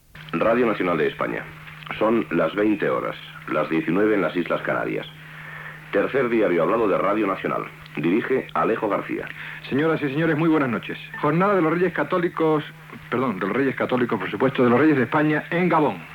Identificació de l'emissora, hora, identificació del programa, titular: visita dels reis d'Espanya al Gabon (amb un lapsus en la formulació)
Informatiu